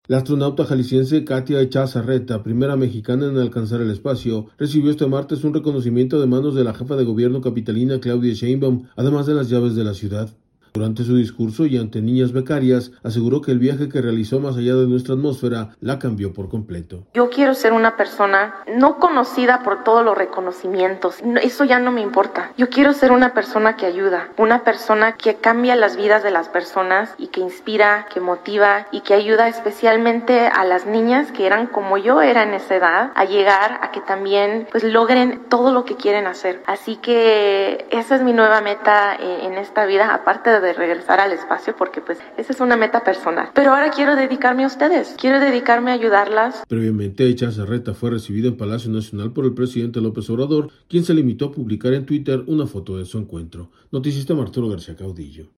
audio La astronauta jalisciense Katya Echazarreta, primera mexicana en alcanzar el espacio, recibió este martes un reconocimiento de manos de la Jefa de Gobierno capitalina, Claudia Sheinbaum, además las llaves de la ciudad. Durante su discurso, y ante niñas becarias, aseguró que el viaje que realizó más allá de nuestra atmósfera, la cambió por completo.